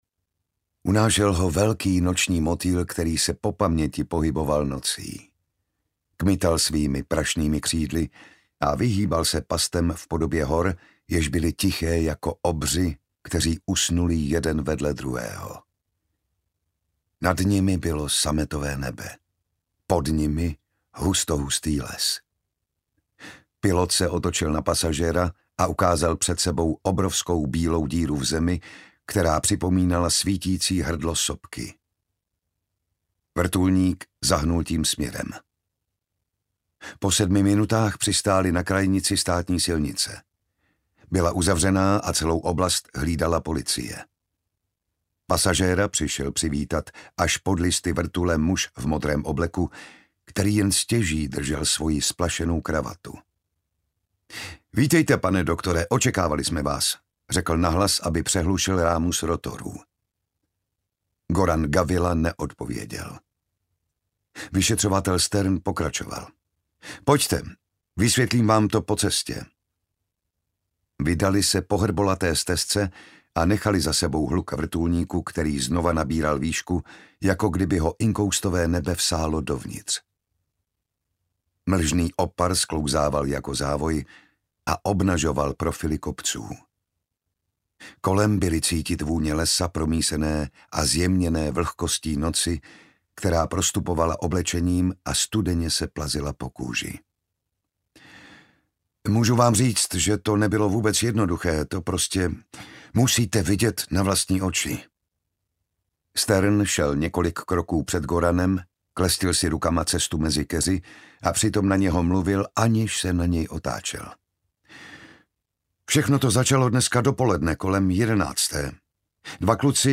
Našeptávač audiokniha
Ukázka z knihy
• InterpretJan Šťastný